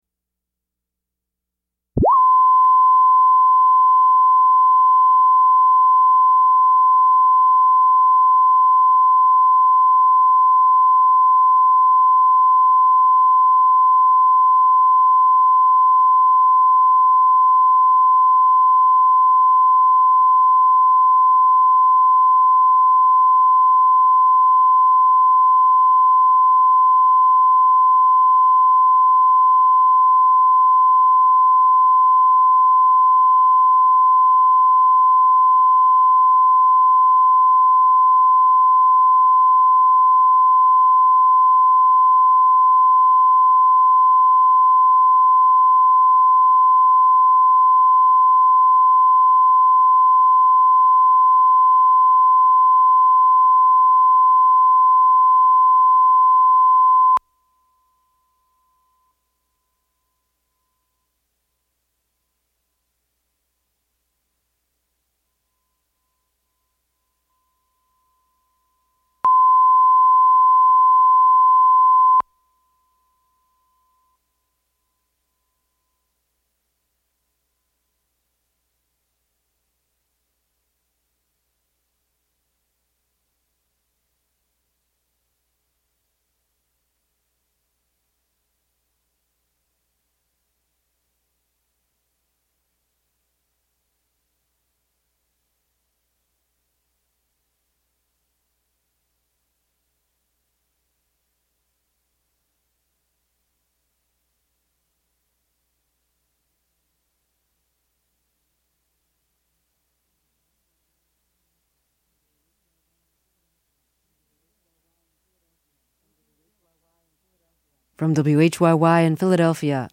Terry Gross is the host and an executive producer of Fresh Air, the daily program of interviews and reviews. It is produced at WHYY in Philadelphia, where Gross began hosting the show in 1975, when it was broadcast only locally.